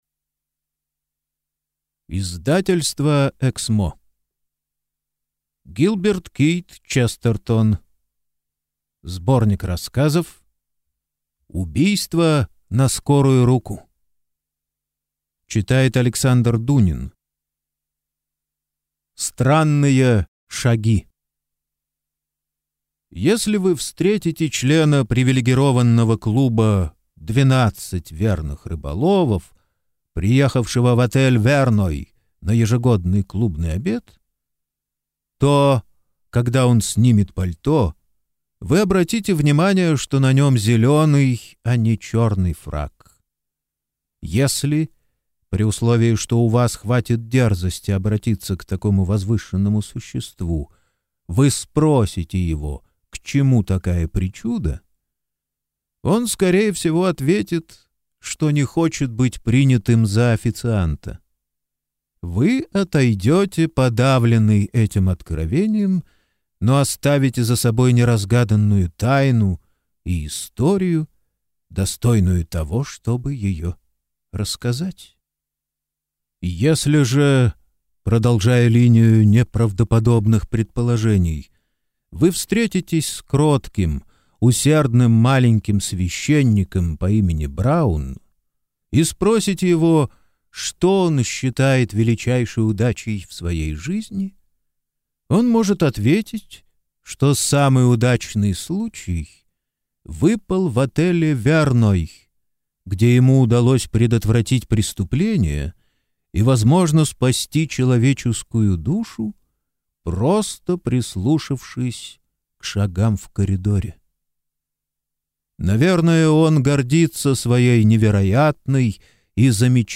Аудиокнига Убийство на скорую руку | Библиотека аудиокниг